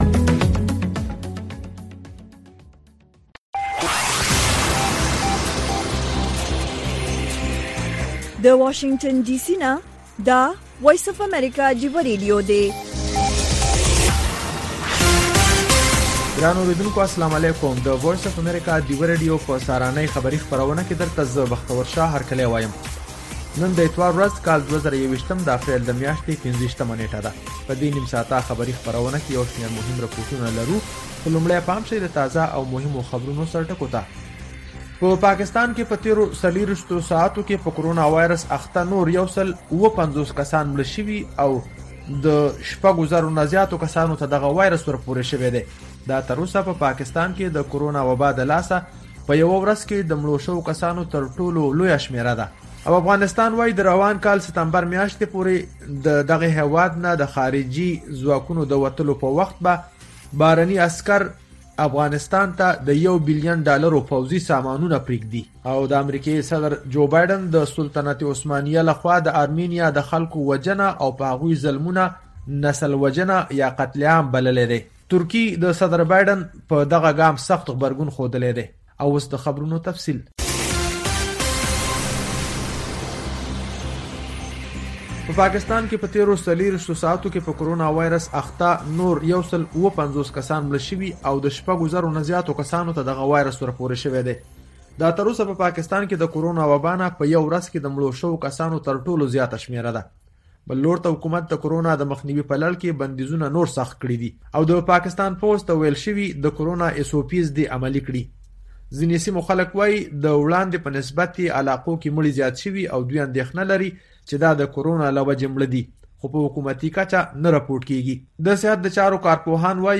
د وی او اې ډيوه راډيو سهرنې خبرونه چالان کړئ اؤ د ورځې دمهمو تازه خبرونو سرليکونه واورئ.